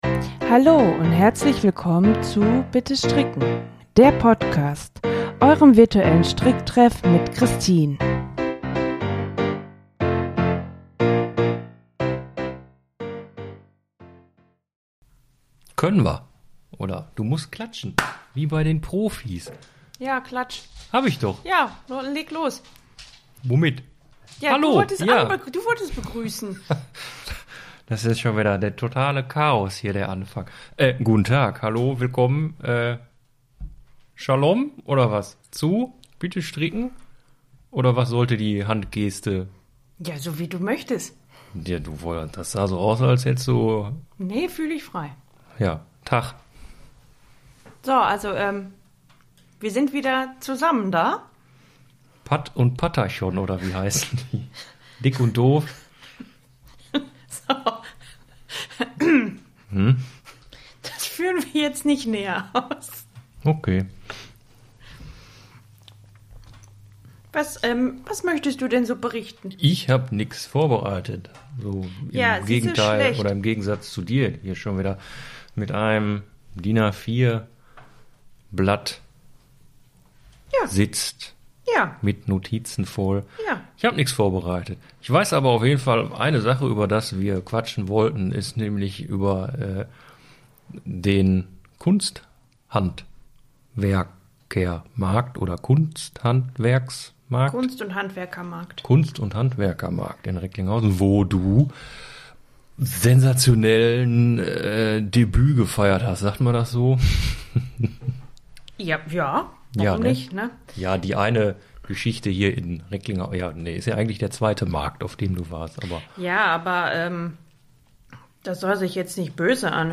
Ich habe den Agnete Cardigan versaut und meine Meinung über wildernde Socken ist auch gespalten. Hört rein und lauscht den lieblichen Stimmen von Mann und mir.